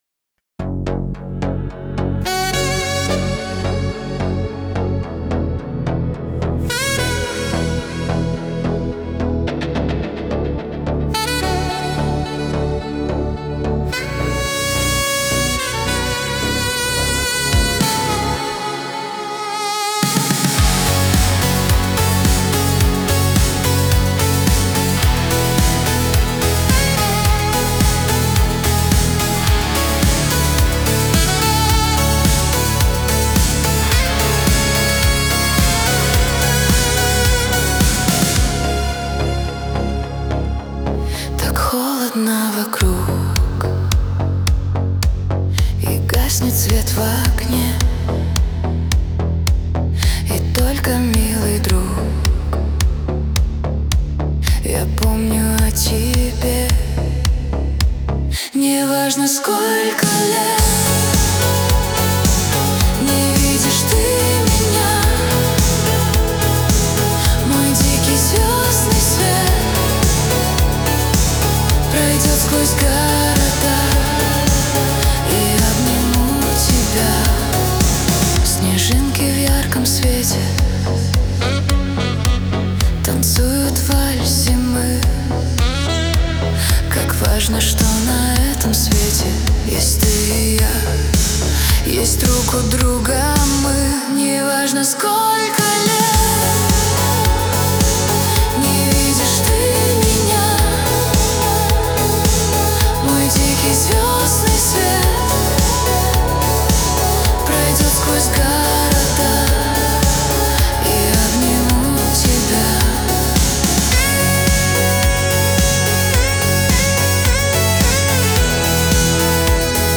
диско , pop